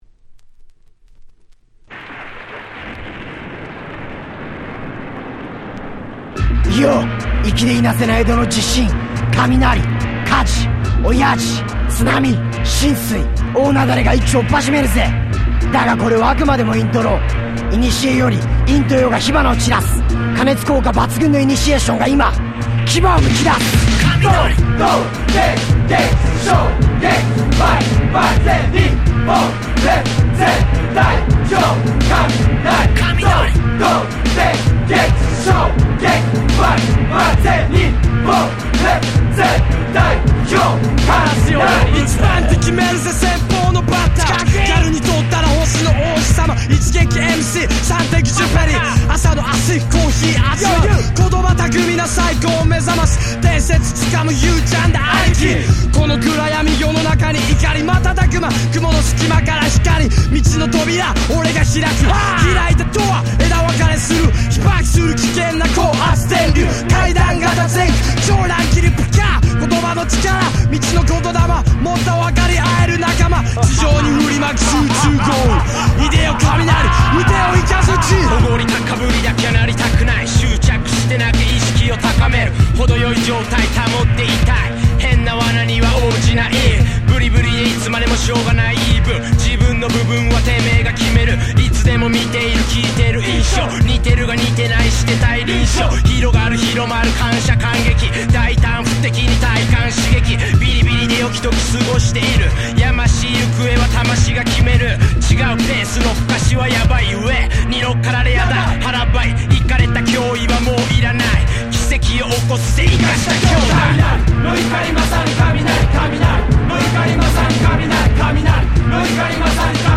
97' Very Nice Japanese Hip Hop !!
90's J-Rap 日本語ラップ